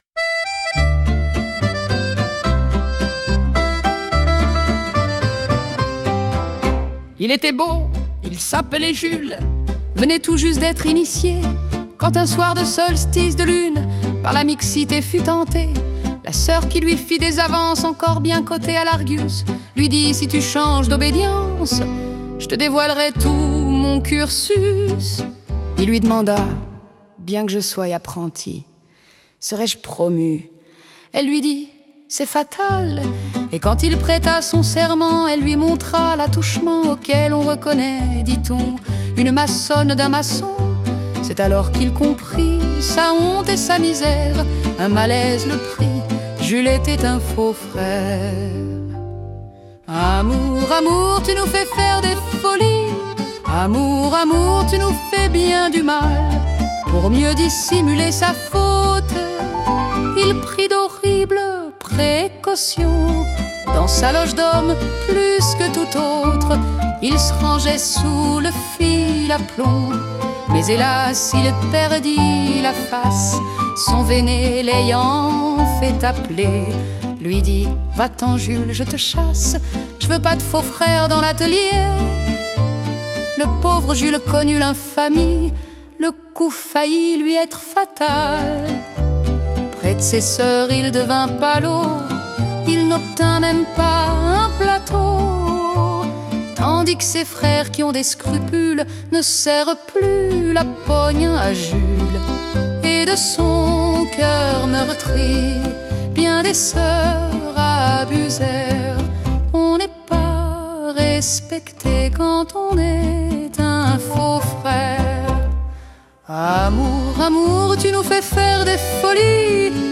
Enregistrement à capella
Création Auditus Mysticus